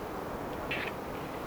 Onko se jokin hömötiaislaji?